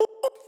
Tm8_Chant30.wav